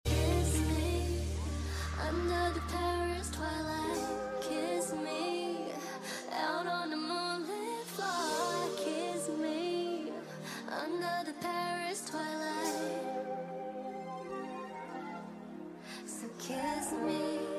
壓軸現身 Louis Vuitton 2025 春夏大秀，現場尖叫聲不斷！